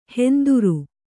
♪ henduru